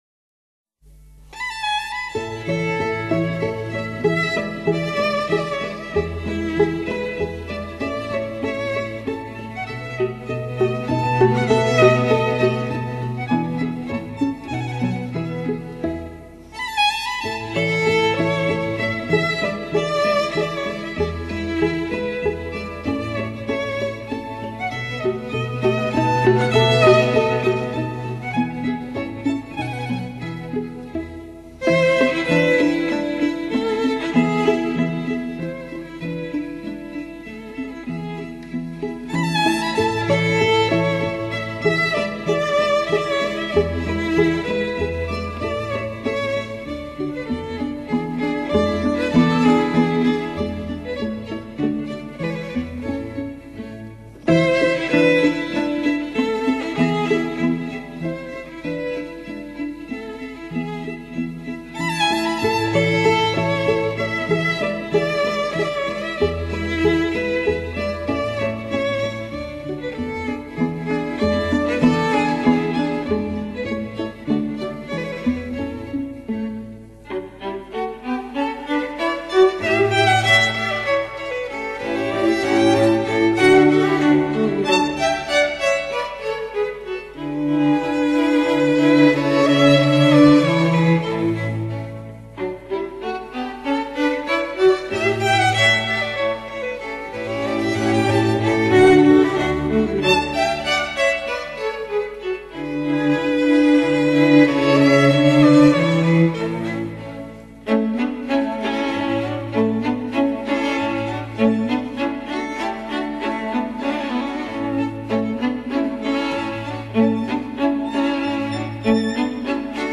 专辑语言：纯音乐
小提琴悠扬清丽
中提琴忧郁黯淡
大提琴浑厚明亮